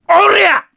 Baudouin-Vox_Attack2_jp.wav